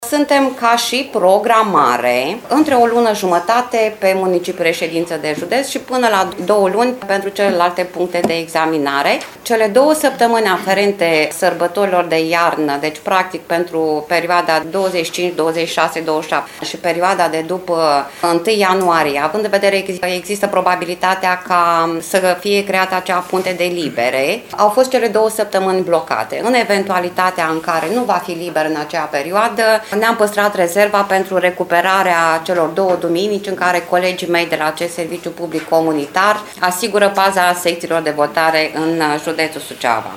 Prefectul MIRELA ADOMNICĂI a declarat că activitatea Serviciului Permise Auto “s-a îmbunătățit semnificativ în ultima perioadă”, durata de așteptare scăzând semnificativ.